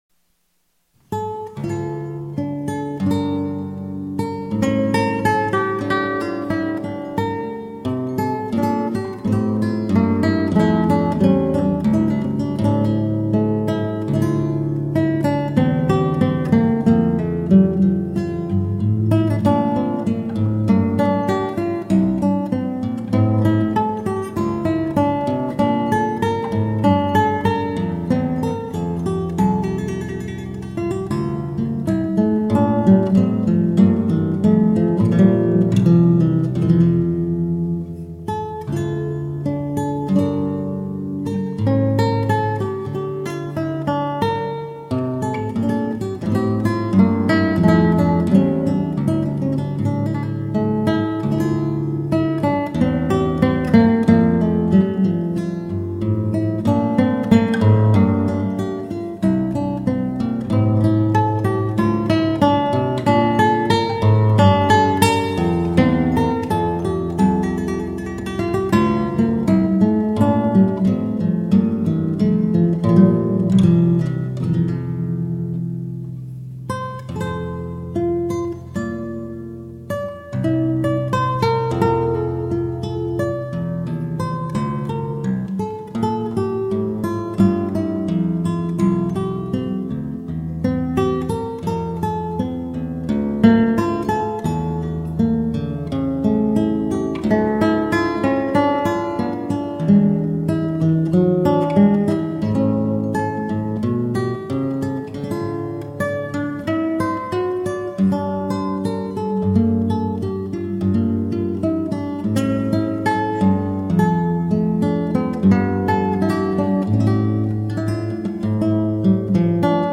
Classical, Baroque, Instrumental, Lute